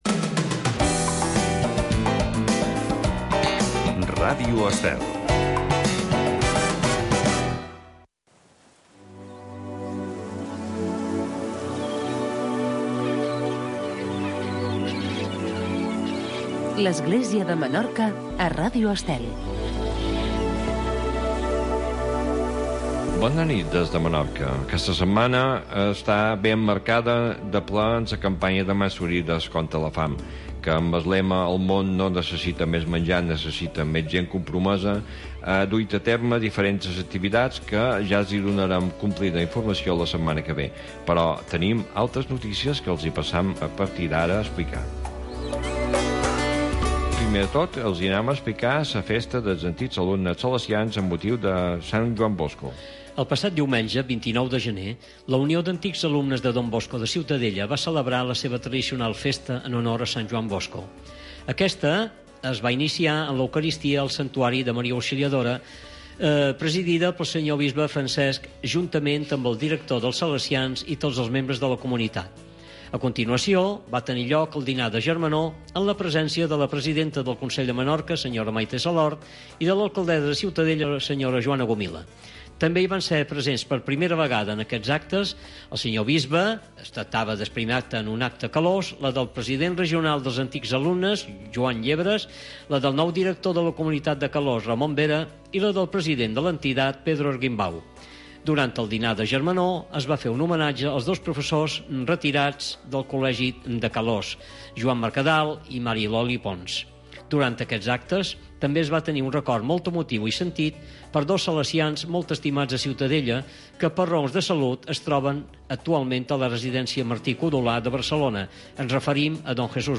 Informatiu d’actualitat cristiana del bisbat de Menorca.